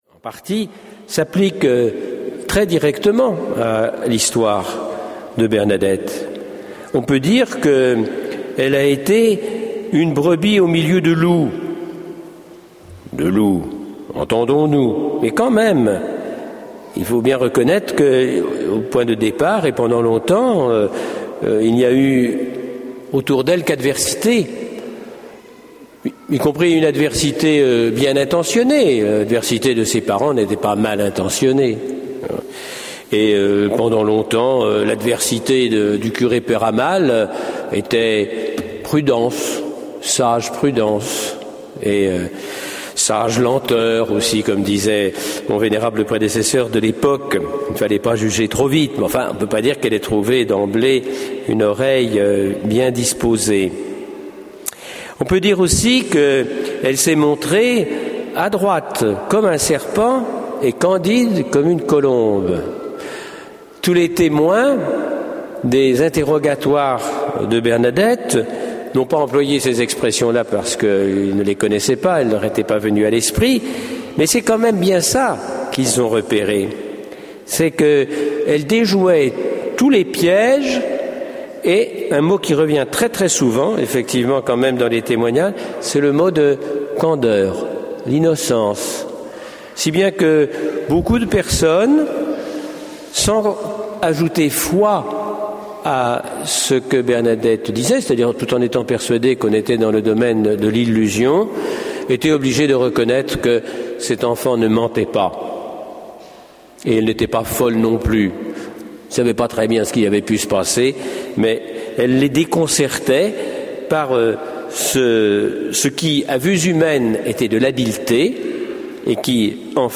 Lourdes 2009-01 Hom�lie [ Mgr Jacques Perrier R�f: E002552 Produit original: Maria Multi M�dia AU01368] - 3.00 EUR : Maria Multi M�dia, T�l�chargement de retraites, enseignements, conf�rences, chants, musiques
Maria Multi M�dia Lourdes 2009-01 Hom�lie [ Mgr Jacques Perrier R�f: E002552 Produit original: Maria Multi M�dia AU01368] - Enregistr� en 2009 (Session B�atitudes Lourdes 10-14 juillet 2009